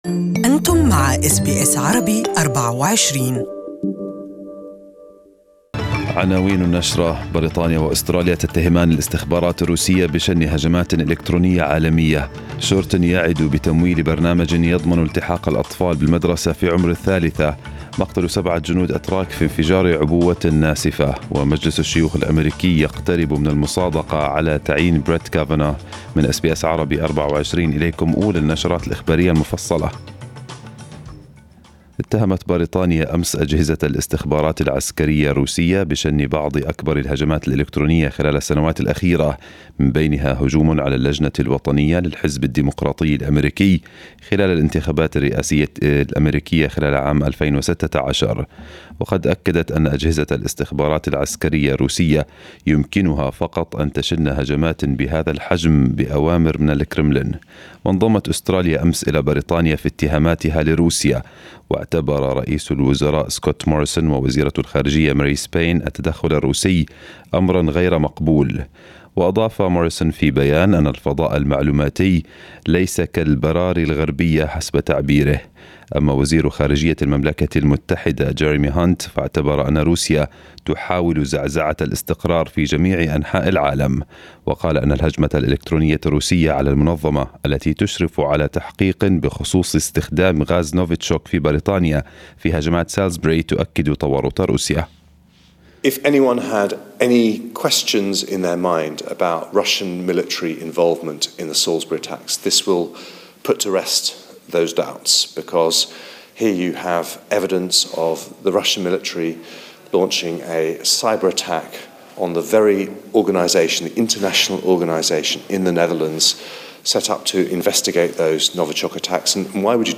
News bulletin in Arabic